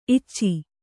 ♪ icci